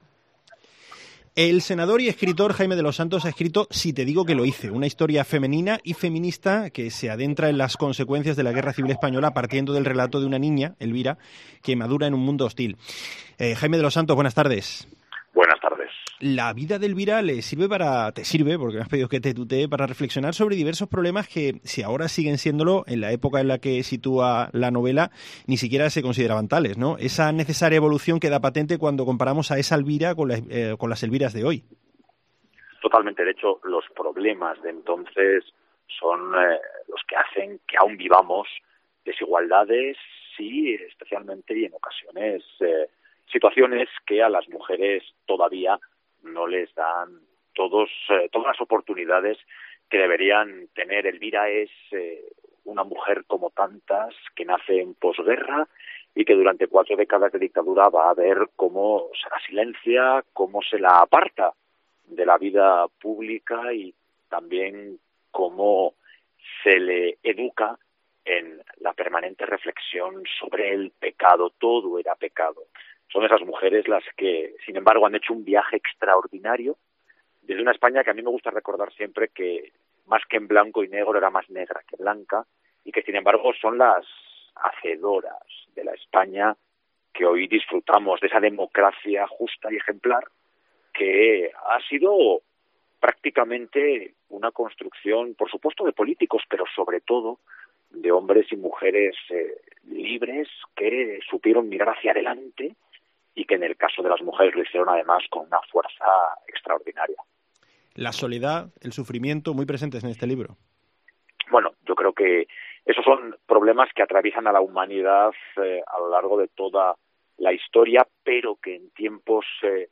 Entrevista Jaime de los Santos